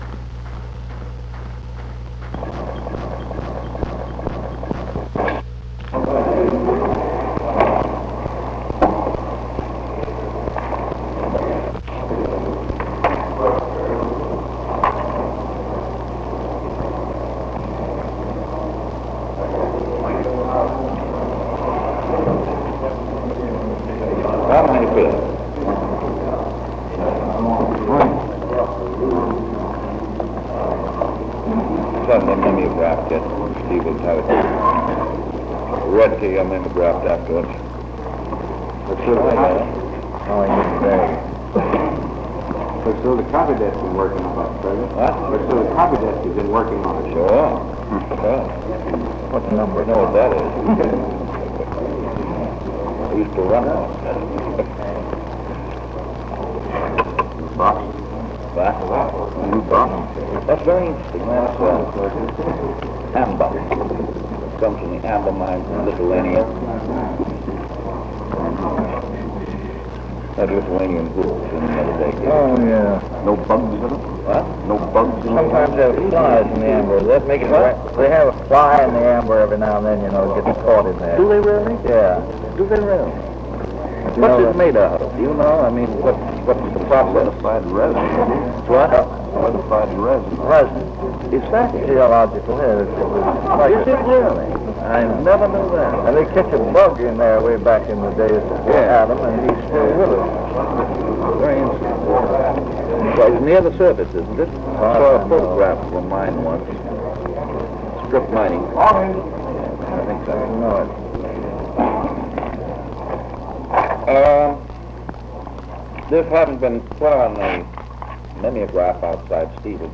Press Conference 690
Secret White House Tapes | Franklin D. Roosevelt Presidency